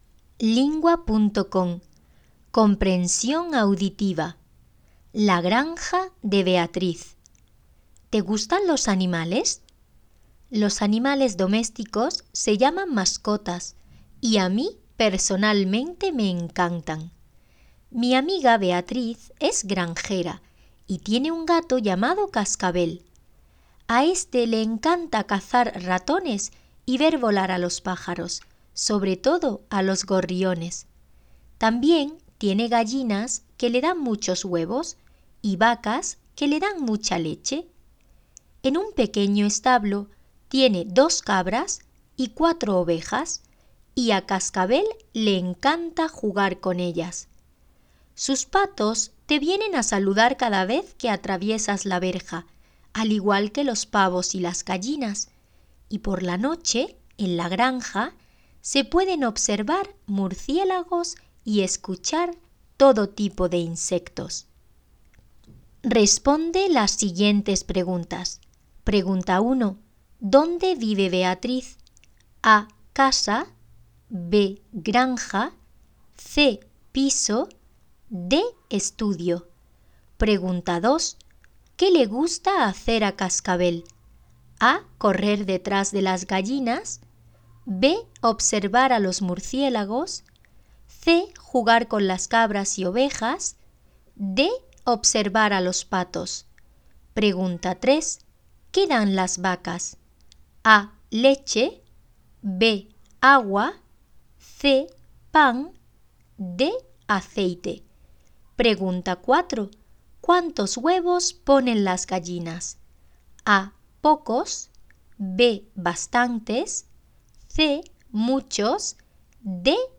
Hiszpania